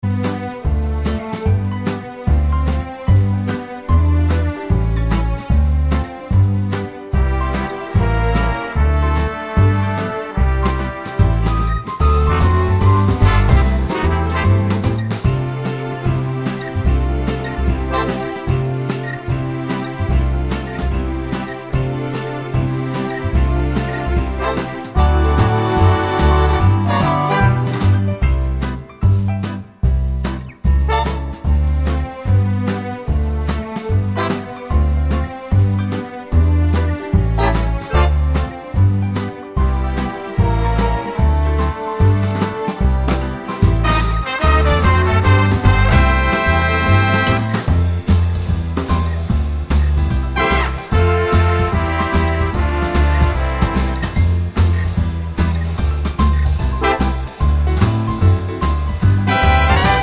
no Backing Vocals Christmas 2:40 Buy £1.50